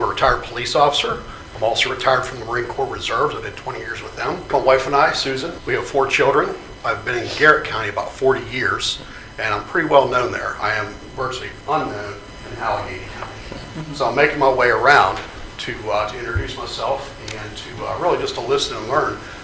District 1-A Candidate At Frostburg Council Meeting